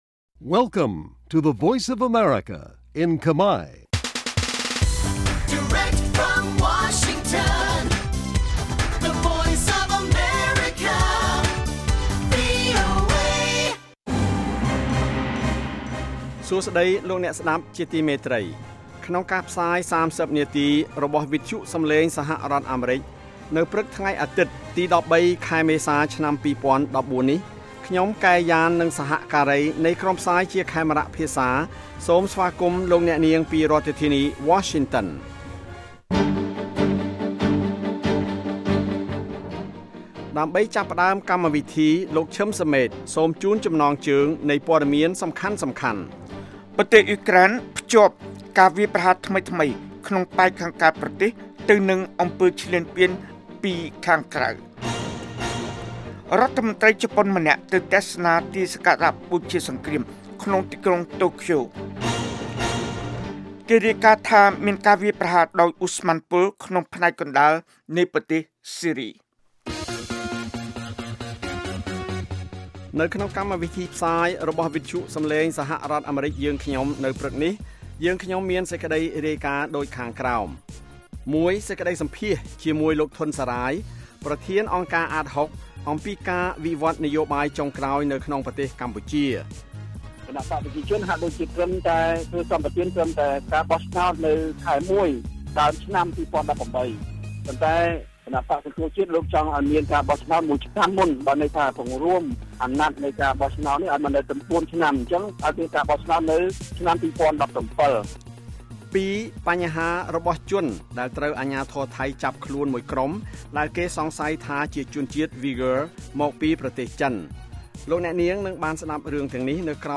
នេះជាកម្មវិធីផ្សាយប្រចាំថ្ងៃតាមវិទ្យុជាភាសាខ្មែរ រយៈពេល ៣០នាទី ដែលផ្តល់ព័ត៌មានអំពីប្រទេសកម្ពុជានិងពិភពលោក ក៏ដូចជាព័ត៌មានពិពណ៌នា ព័ត៌មានអត្ថាធិប្បាយ និងបទវិចារណកថា ជូនដល់អ្នកស្តាប់ភាសាខ្មែរនៅទូទាំងប្រទេសកម្ពុជា។ កាលវិភាគ៖ ប្រចាំថ្ងៃ ម៉ោងផ្សាយនៅកម្ពុជា៖ ៥:០០ ព្រឹក ម៉ោងសកល៖ ២២:០០ រយៈពេល៖ ៣០នាទី ស្តាប់៖ សំឡេងជា MP3